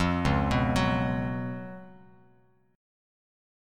C#11 chord